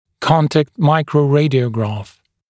[‘kɔntækt ˌmaɪkrəu’reɪdɪəugrɑːf]